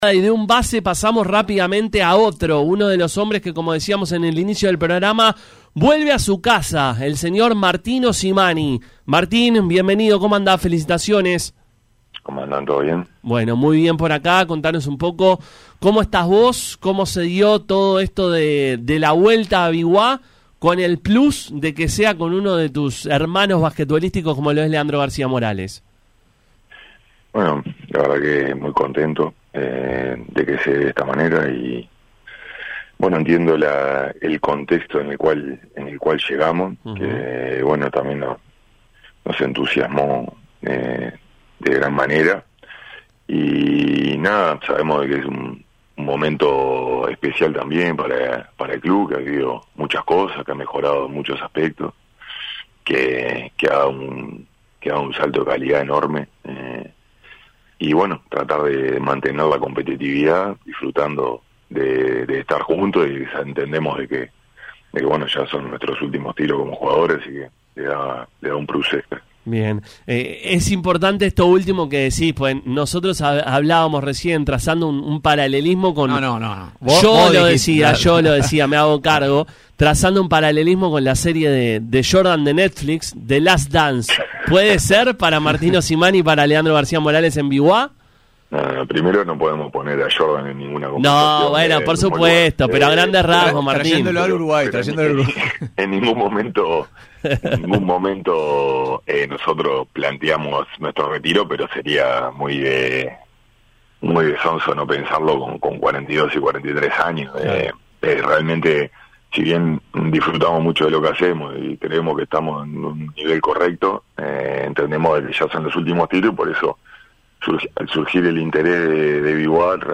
Martín Osimani habló con Pica La Naranja luego de convertirse en nuevo jugador de Biguá.